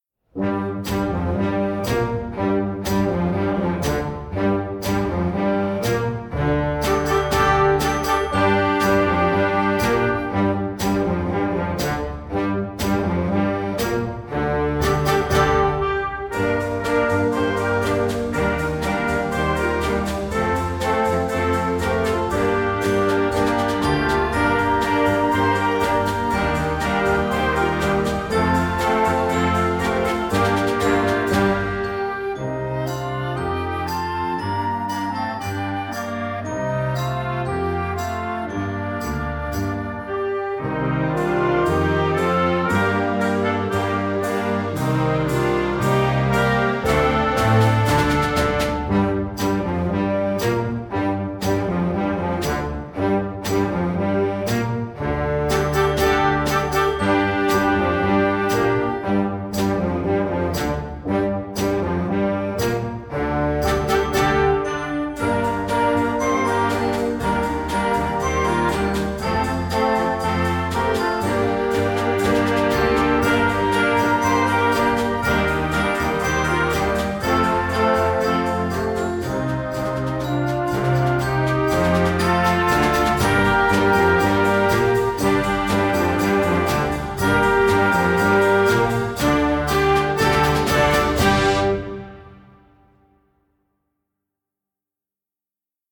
Gattung: Konzertwerk für Jugendblasorchester
Besetzung: Blasorchester
ist ein Rockstück mit einem eingängigen Bass-Riff